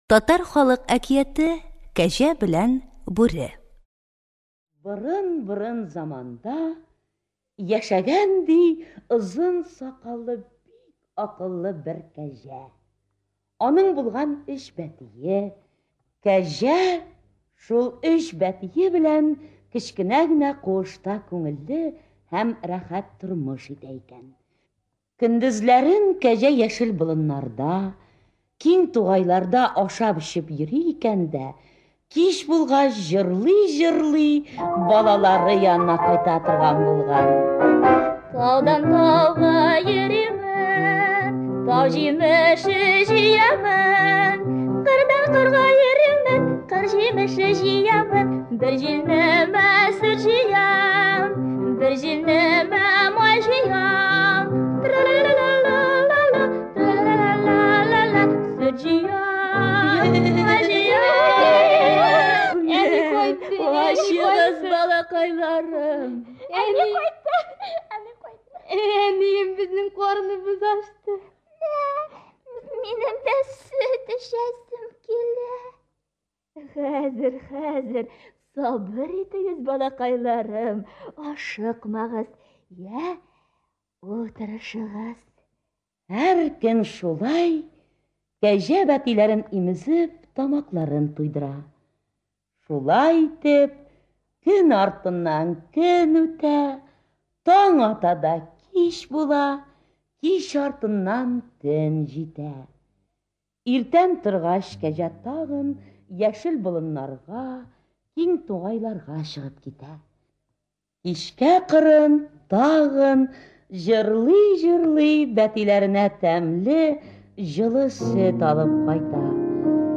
Кәҗә белән Бүре. Татар әкиятләре.